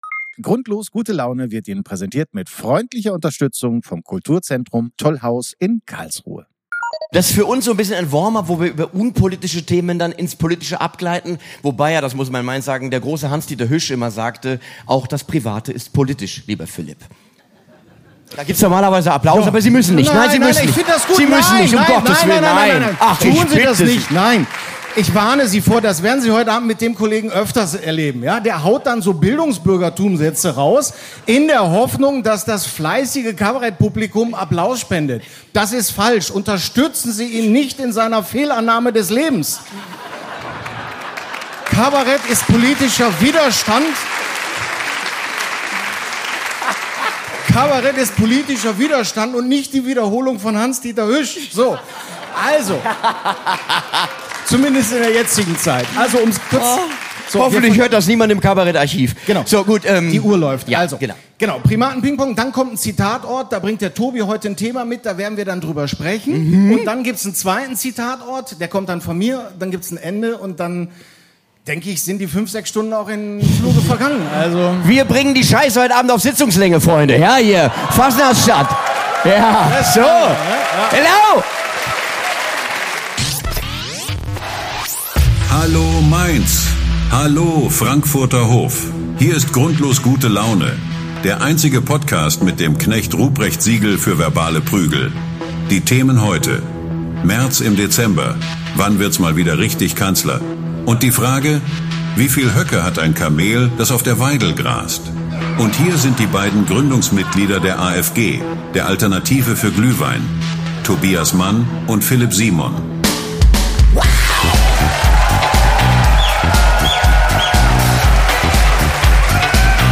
Die Tragik des Wiederaufstehens (LIVE AUS MAINZ) ~ GRUNDLOS GUTE LAUNE Podcast